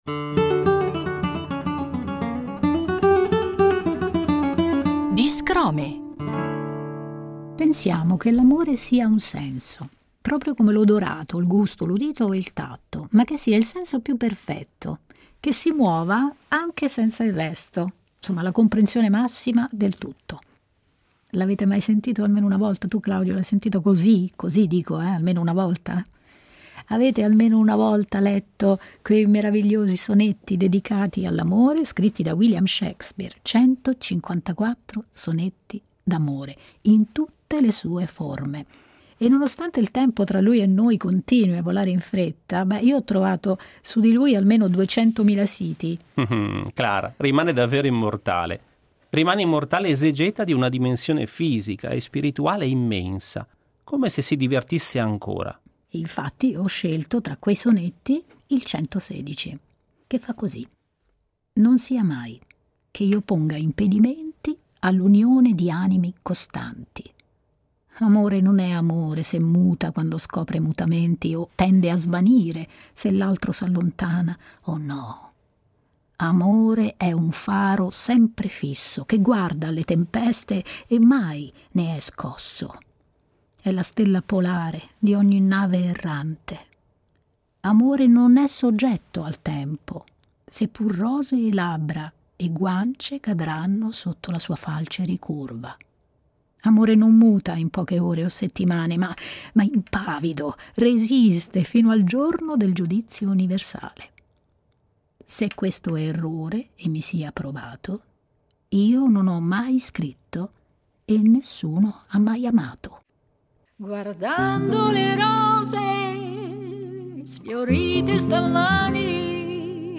porta in scena nel metodo dello spoken word, storie e racconti dei nostri tempi sotto forma di versi, una poesia orale nel battito della musica. Il viaggio racconta storie di un'umanità fragile e forte, innamorata e perduta, che riflette sul valore che può libertà.